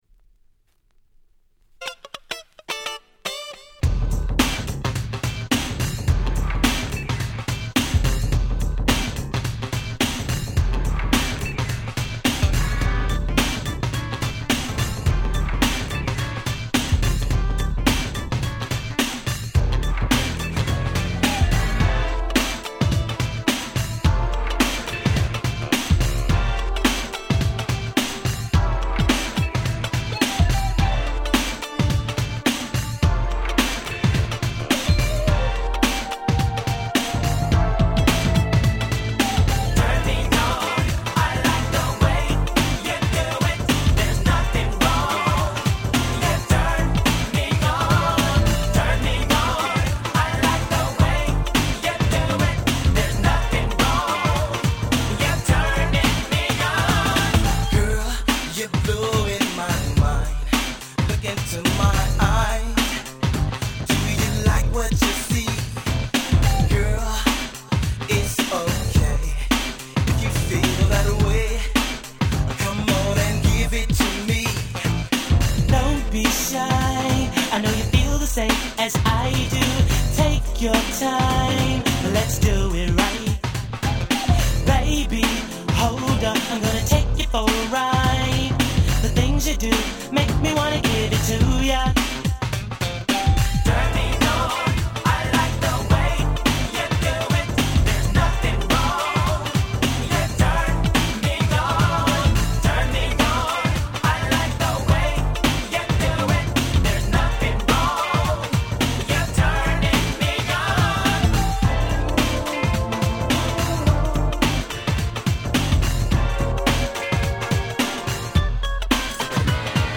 92' 人気New Jack Swing！！